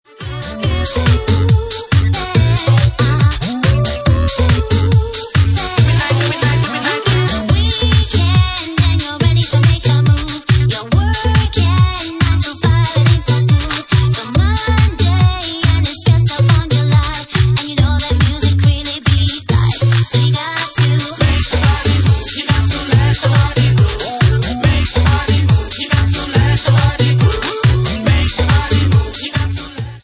Bassline House at 142 bpm